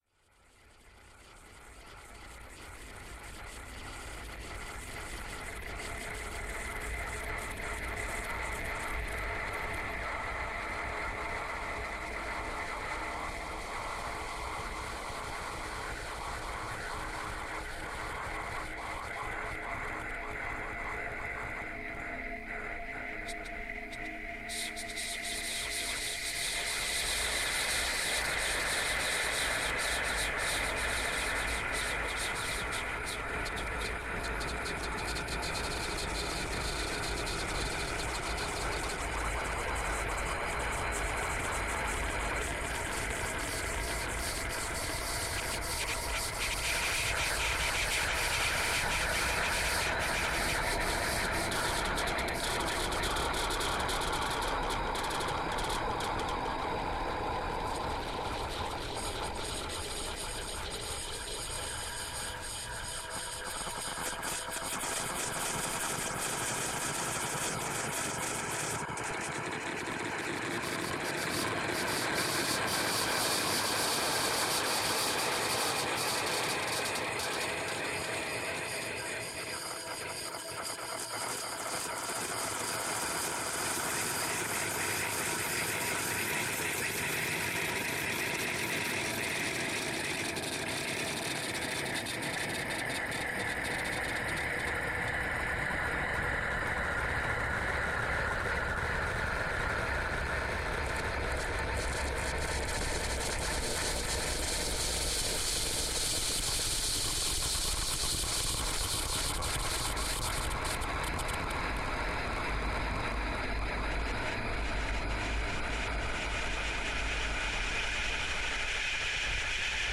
Using granular synthesis a sound layer is created to alienate the voice signal further
Audio Example: Granular synthesis of voice signal
05_Granular.mp3